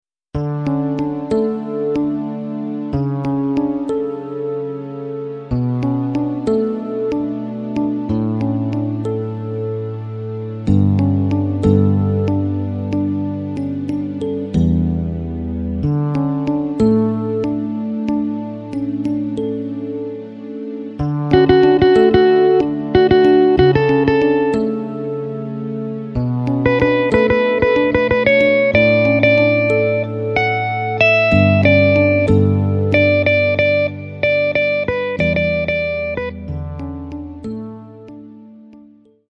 Takt:          4/4
Tempo:         93.00
Tonart:            D